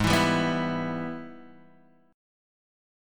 Em/G# chord {4 7 5 4 5 x} chord
E-Minor-Gsharp-4,7,5,4,5,x.m4a